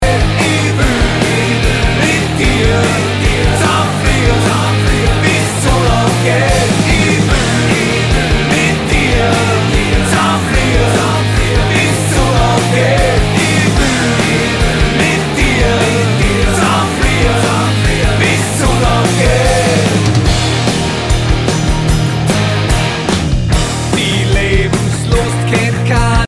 Punkrock